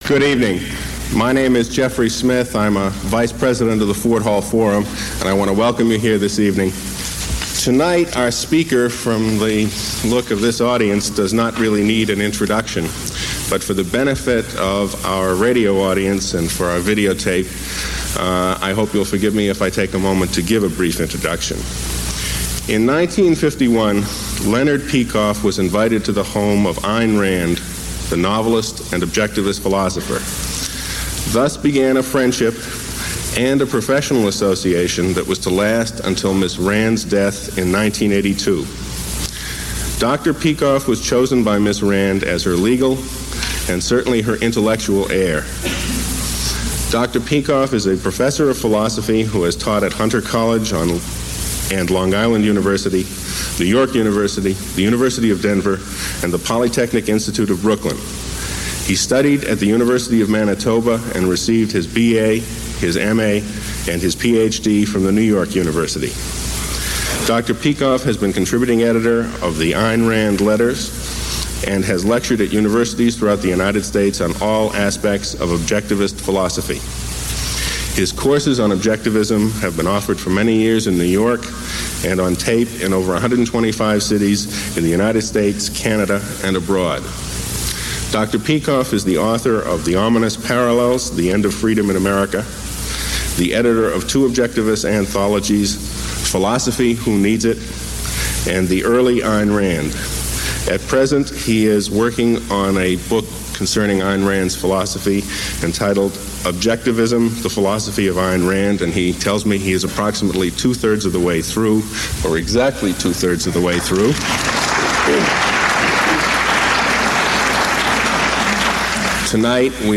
In this lecture, Dr. Peikoff recounts how he met Ayn Rand, how he became her student and later intellectual heir, and what it was like knowing her.
Below is a list of questions from the audience taken from this lecture, along with (approximate) time stamps.